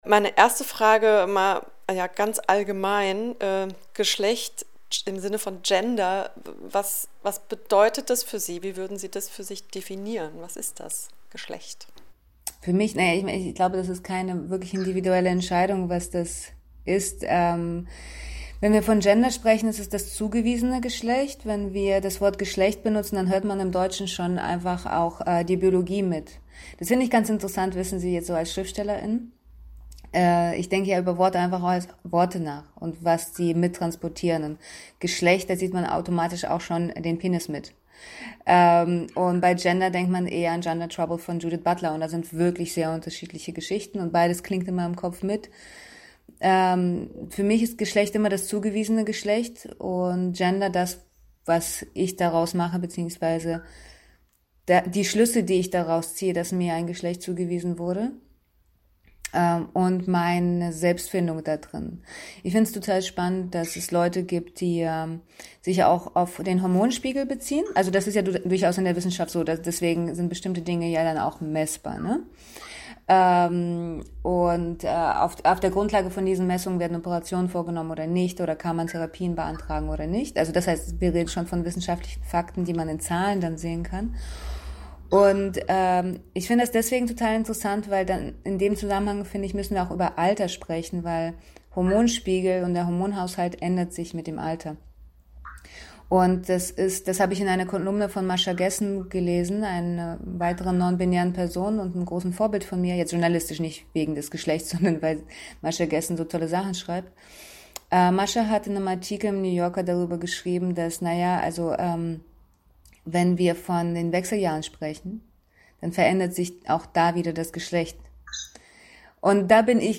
Hier das Interview mit Sasha Marianna Salzmann: